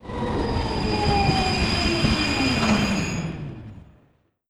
train_stop.wav